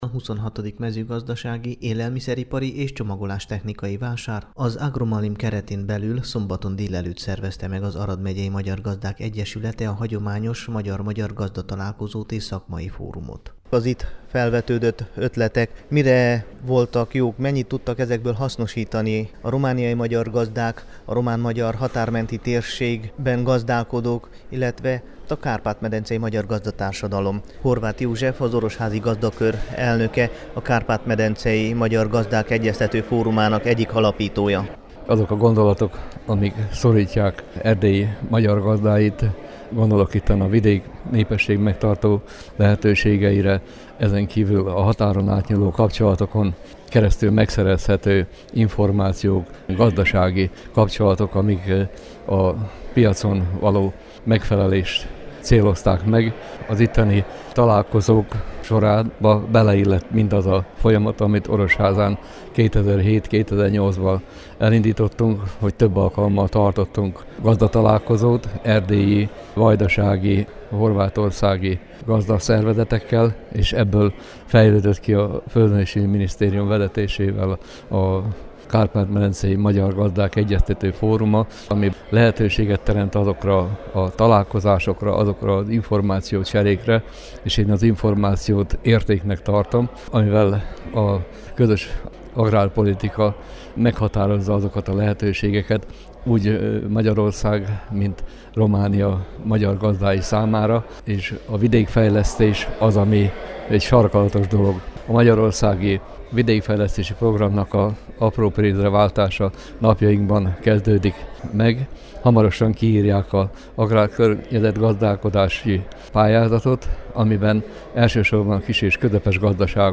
Az idei rendezvényt szombaton délelőtt A víz a mezőgazdaságban mint éltető erő címmel szervezték meg az Expón. Az előadások mellett sokan méltatták Arad szerepét a Kárpát-medencei magyar agrárstratégia alakításában.